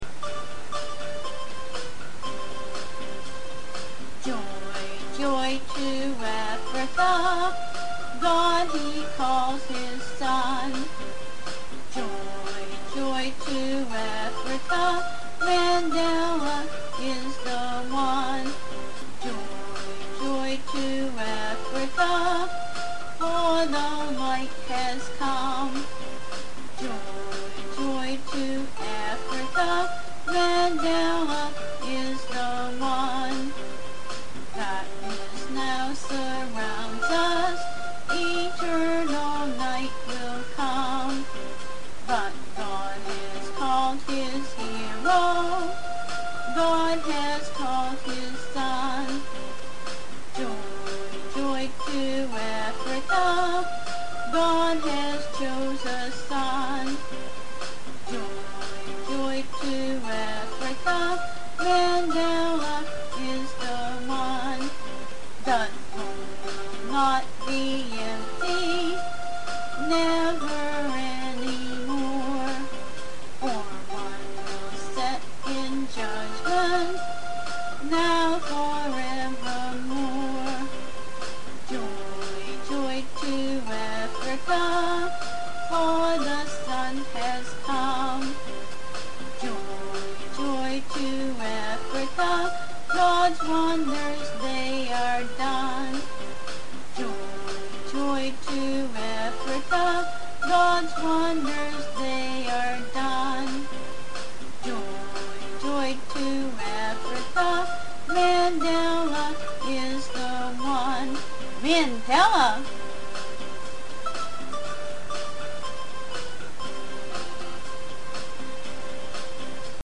accompanied on her keyboard